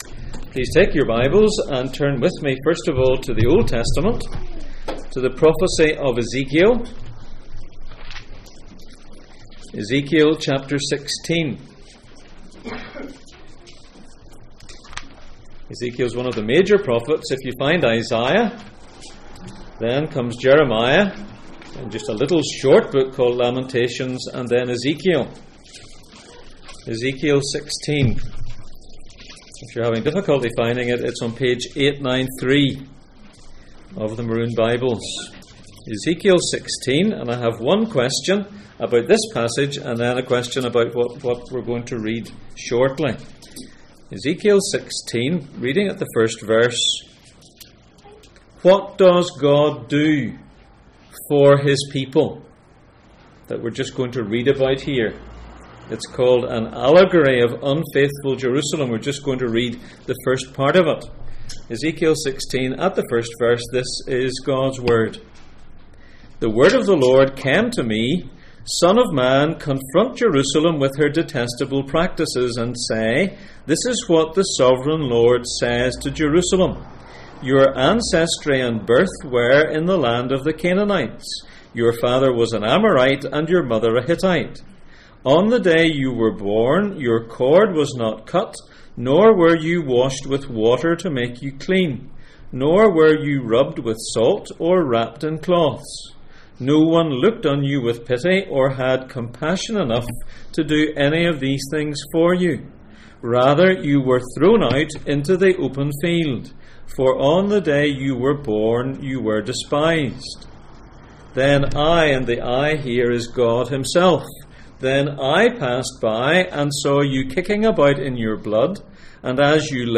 Two Ways to Live Passage: Ezekiel 16:1-14, 1 Corinthians 15:21-22, 2 Corinthians 5:17-6:2, Genesis 3:14-15 Service Type: Sunday Morning